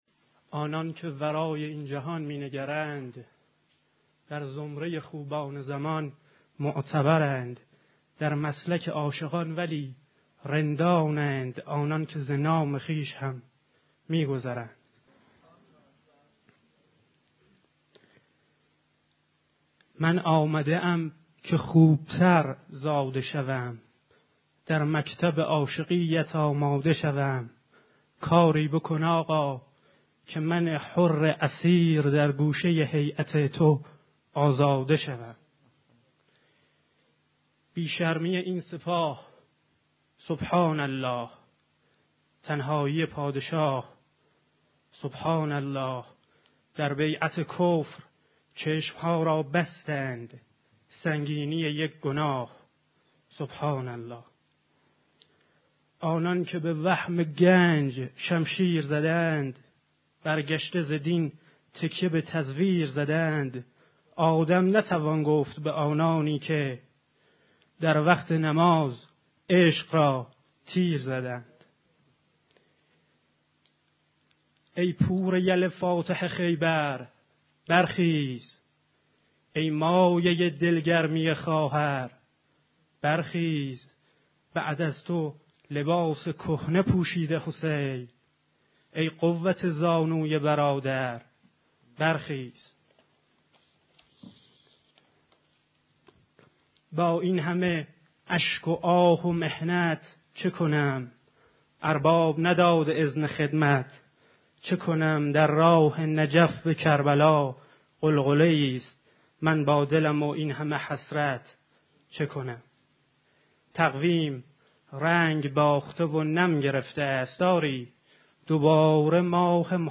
*** سیزدهمین محفل شعری گروه ادبی بداهه در حالی برگزار شد که شاعران حاضر در جلسه در بخش اول به شعر خوانی با موضوع " چهارپاره ها و ترانه های محرمی" و در بخش بعد به شعر خوانی با موضوع آزاد پرداختند.